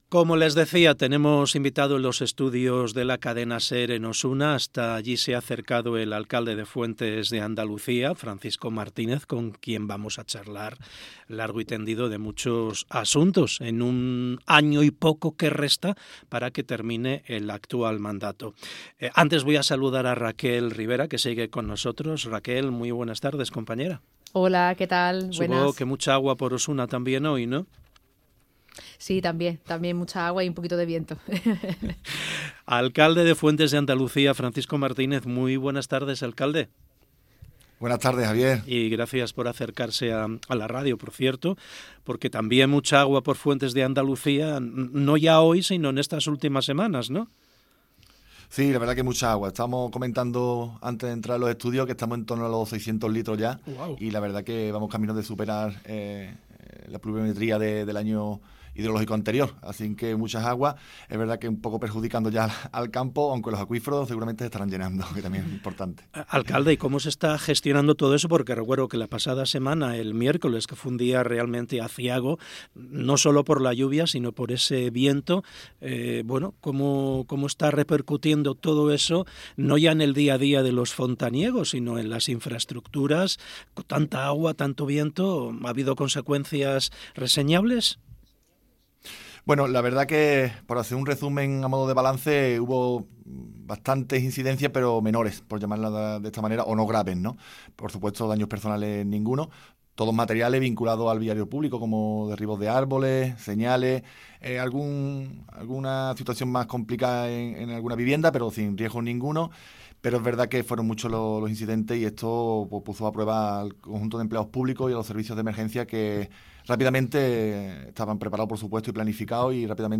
ENTREVISTA FRANCISCO MARTÍNEZ, ALCALDE DE FUENTES DE ANDALUCÍA - Andalucía Centro